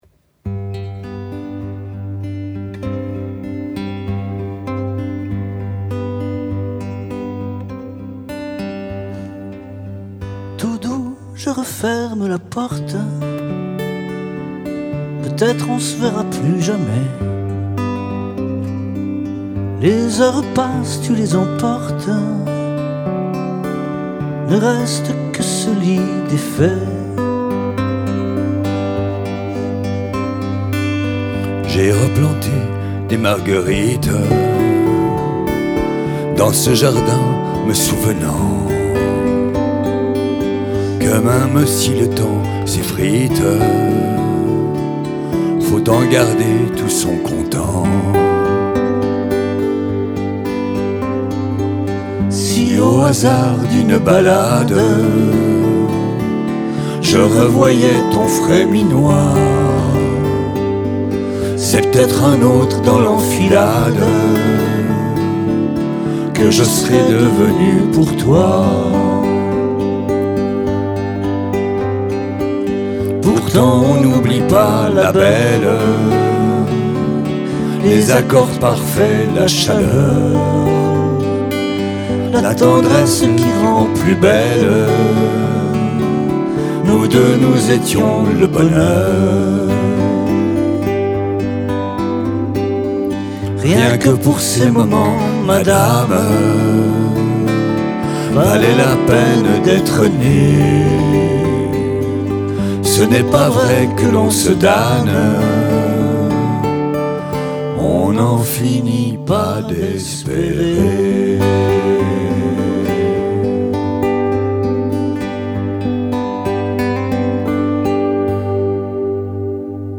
Ce trio est simplement formidable, doux, émouvant.